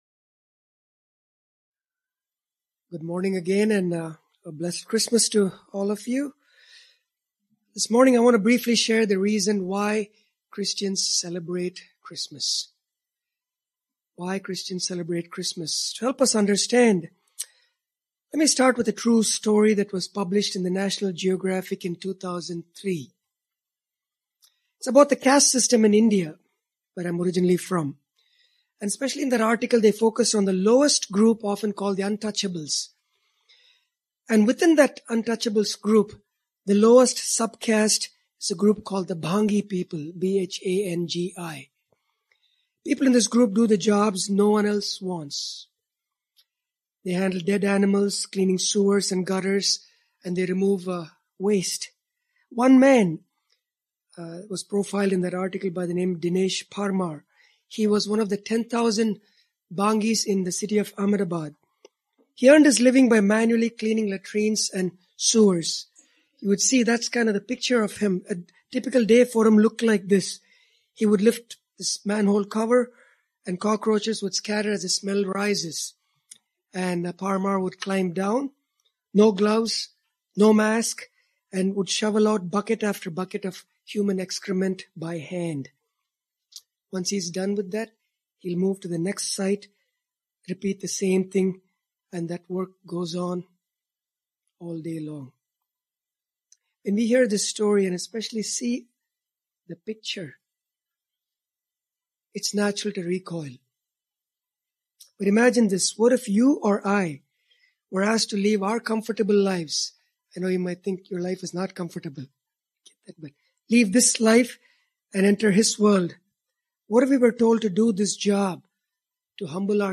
This sermon addresses 3 important truths related to what Jesus has accomplished and what we must do in response.